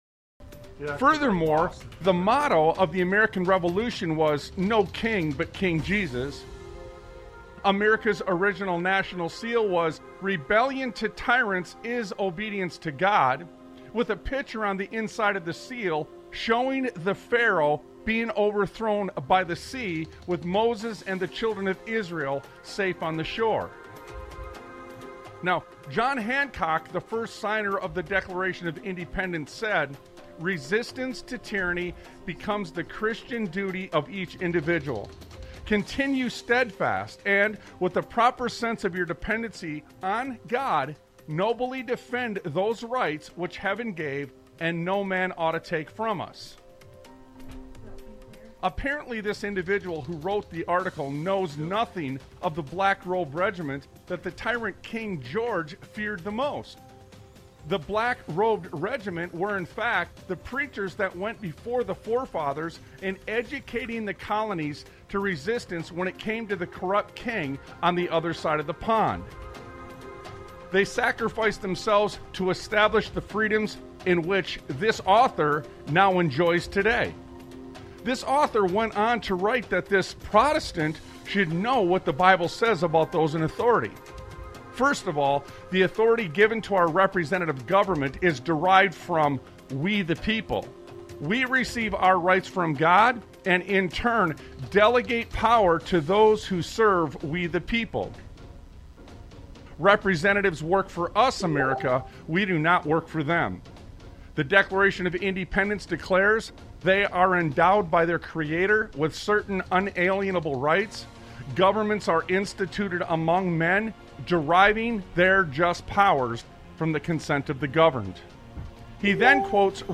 Talk Show Episode, Audio Podcast, Sons of Liberty Radio and Then Why Do The Wicked Rule? on , show guests , about Then Why Do The Wicked Rule?, categorized as Education,History,Military,News,Politics & Government,Religion,Christianity,Society and Culture,Theory & Conspiracy